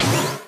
girl_charge_1.wav